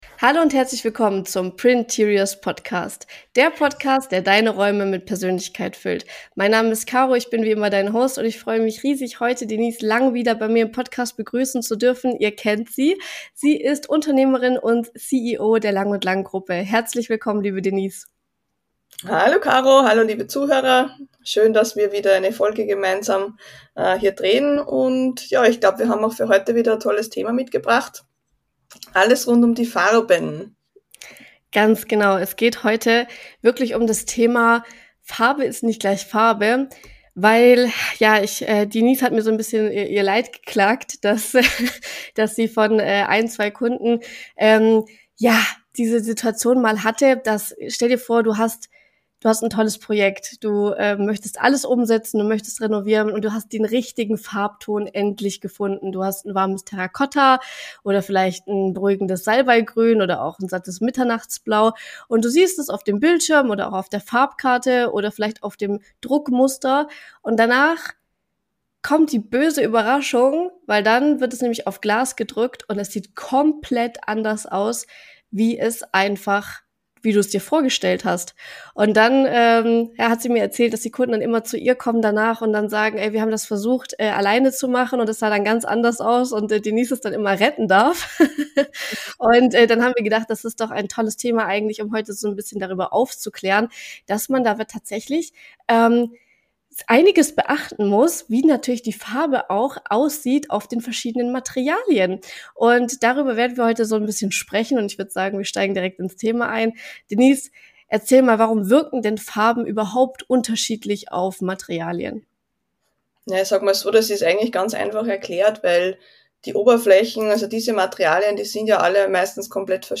Ein spannendes Gespräch über Design, Präzision und das Gefühl für den richtigen Ton.